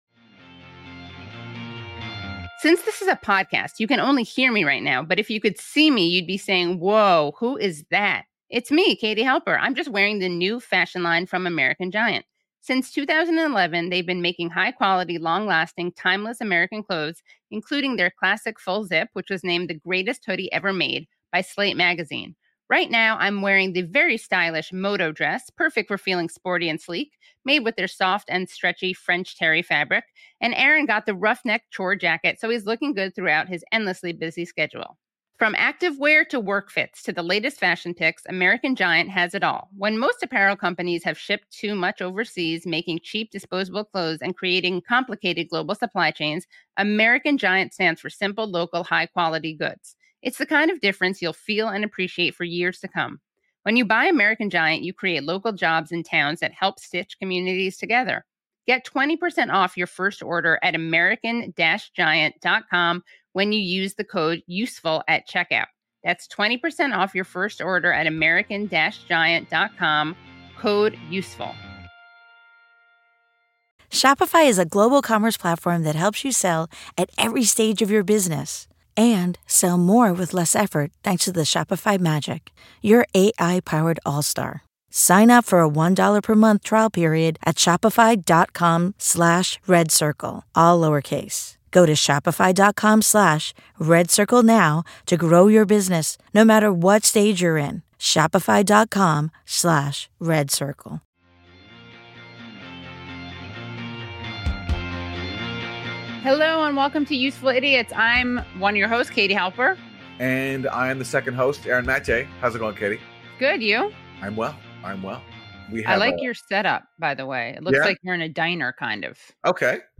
Israel's Illegal Assault on Jenin w/ Noura Erakat (Aaron Maté, Katie Halper interview Noura Erakat; 07 Jul 2023) | Padverb